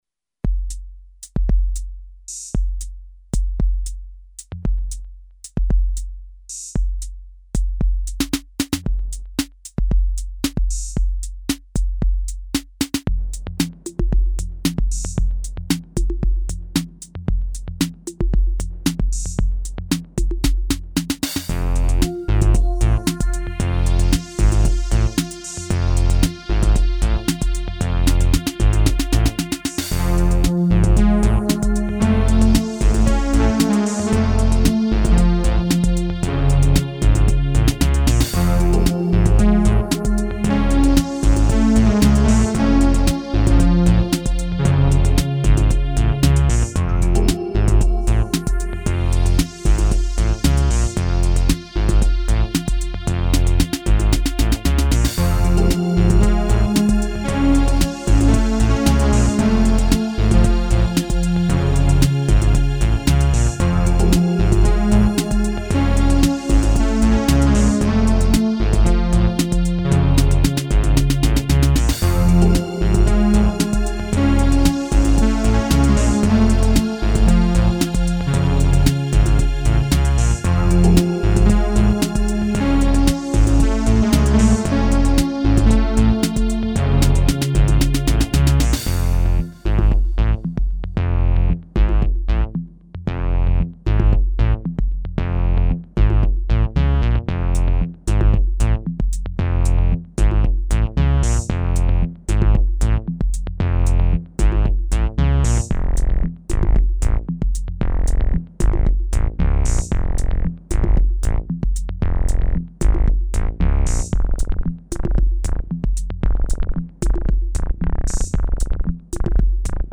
Vill ni ha bas, med betoning på djupbas, så har jag ett spår utlaggt här på faktiskt.
Trinityn (Korg) som är digital tuffar på.
Kan vara av intresse för folk med riggar dom går ned mot 5-10 Hz kanske.
10.8 Hz säger amadeus om ett klipp mellan 1:59 - 2:00.